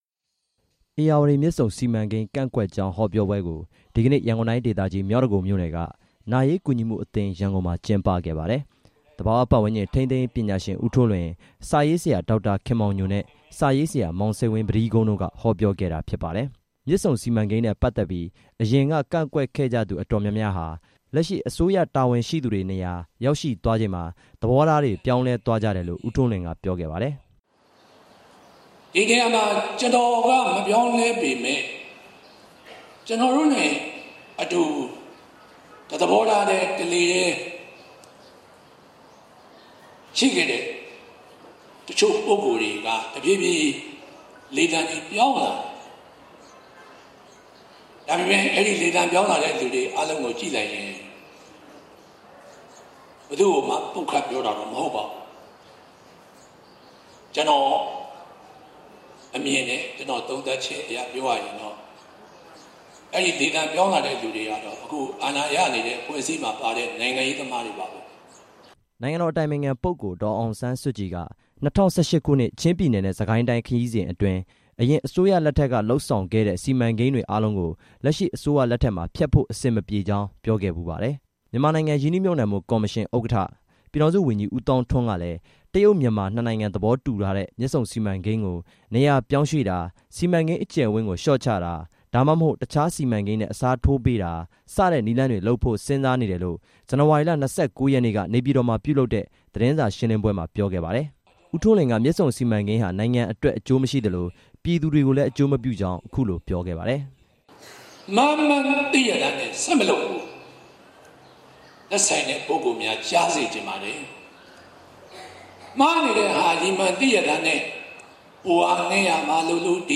ရန်ကုန်တိုင်းဒေသကြီး မြောက်ဒဂုံမြို့နယ်က နာရေးကူညီမှုအသင်း(ရန်ကုန်)မှာ ဒီနေ့ ကျင်းပတဲ့ မြစ်ဆုံစီမံကိန်း ကန့်ကွက်တဲ့ ဟောပြောပွဲမှာ ဦးထွန်းလွင်က ပြောခဲ့တာပါ။